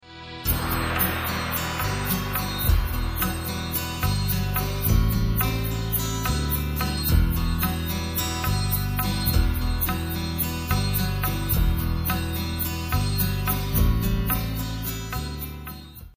intense category-defying instrumentals